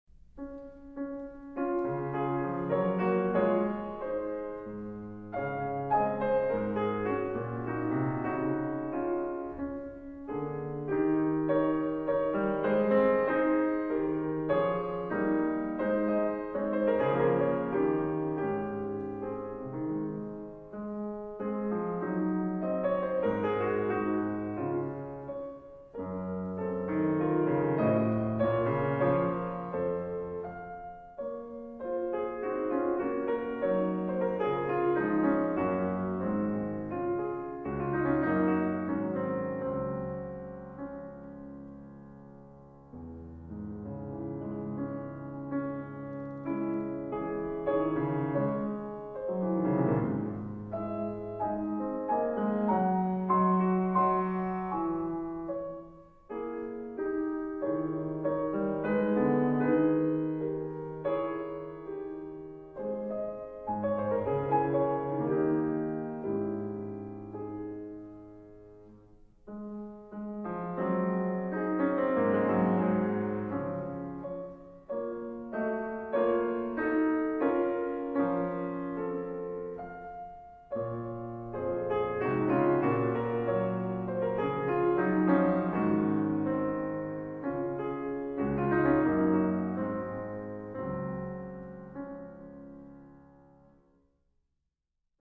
Thema mit Variationen
Cis-Dur